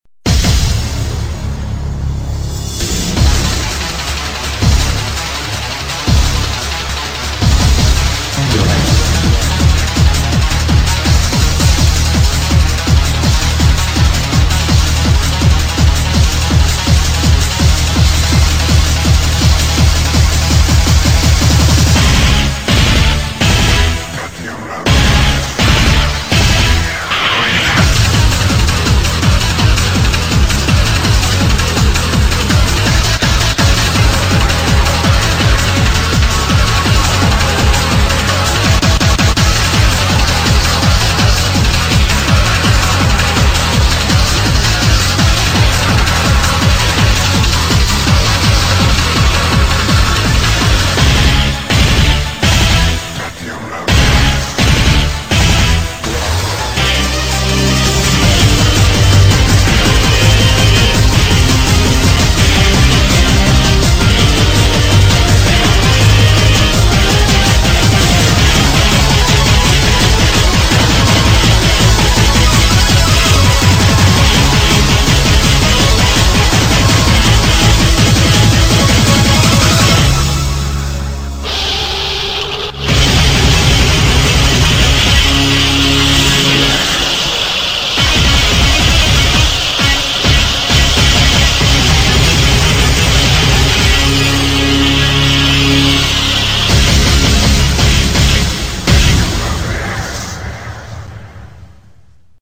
BPM165--1
Audio QualityPerfect (High Quality)